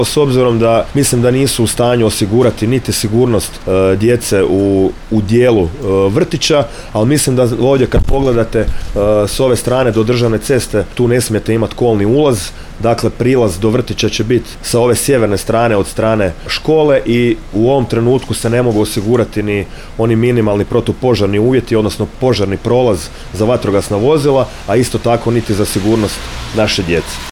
Gradska organizacija HDZ-a Sisak održala je u petak, 22. studenog 2024. godine, tiskovnu konferenciju ispred odgojno-obrazovnog kompleksa u Galdovačkoj ulici, na temu: „Poziv na otvorenje vrtića u Galdovu”.